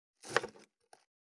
526切る,包丁,厨房,台所,野菜切る,咀嚼音,ナイフ,調理音,まな板の上,料理,
効果音厨房/台所/レストラン/kitchen食器食材